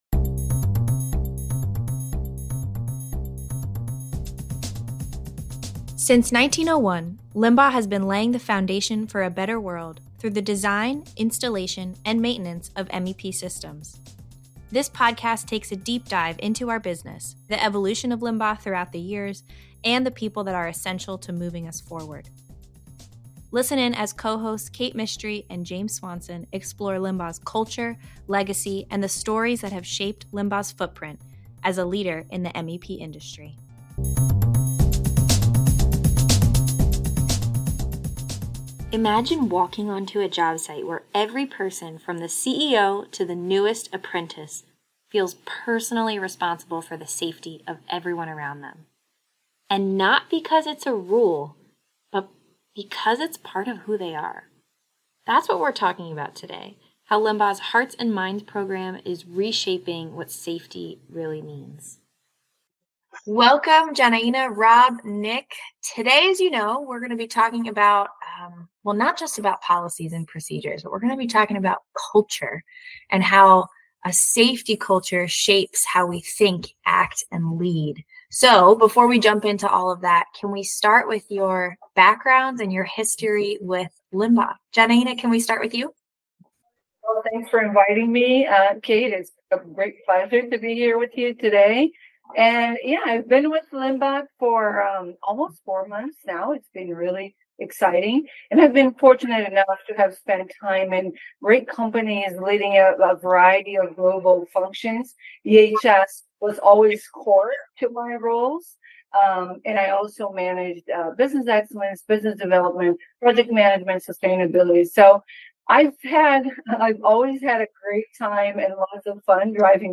In this episode, we uncover how a Hearts & Minds Commitment to Safety goes beyond compliance to create a true culture of shared responsibility. Join us to hear from a few of our Environmental, Health & Safety experts as they share about our history with safety (4:55), stand-outs from our safety culture and its evolution from avoiding accidents to building a culture of accountability and continuous improvement (5:49), opportunities for us and for you (7:40), driving accountability after formal training (11:34), and progress on some critical key initiatives like our “Stop Work” (15:45) and “Do Not Disturb” (23:40) campaigns.